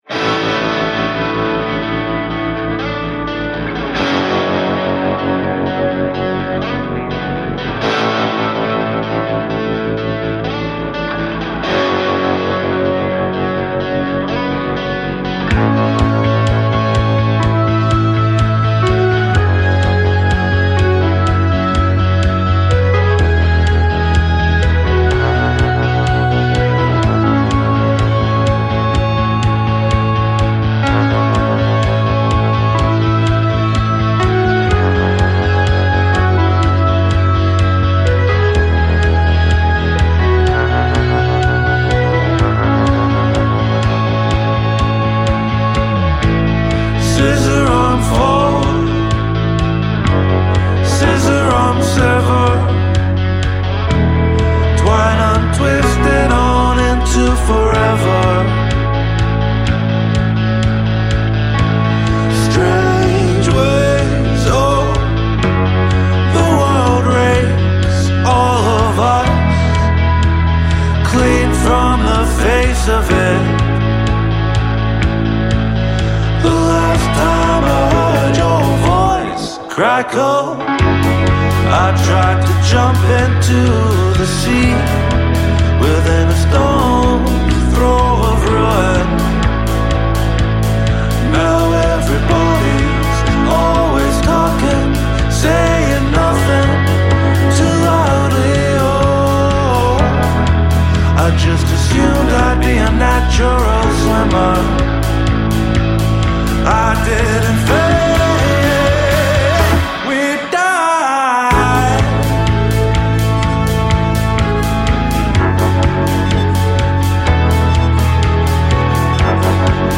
heartfelt collection of indie-rock ballads